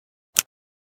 pm_holster.ogg